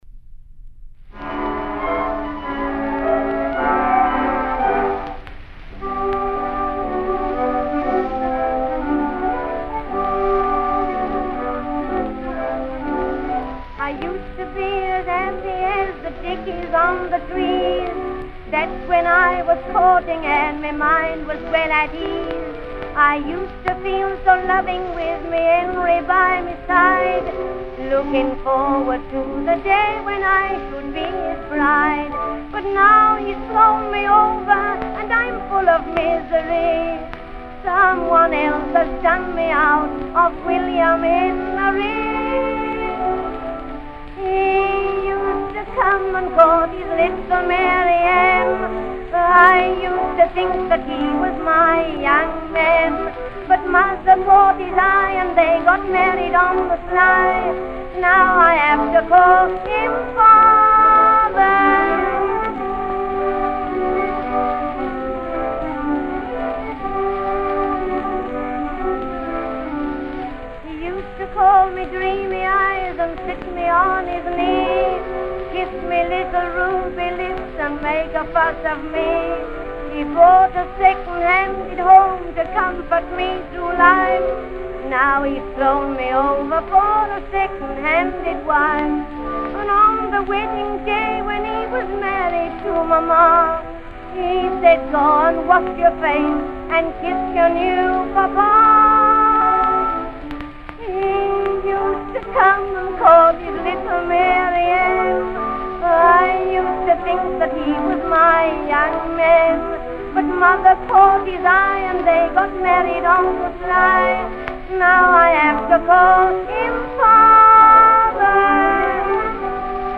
The plaintive chorus goes: